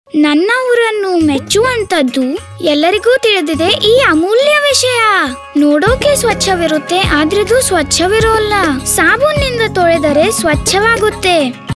Handwashing with soap before eating - Clean India Radio PSA (Kannada)